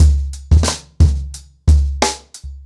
TrackBack-90BPM.25.wav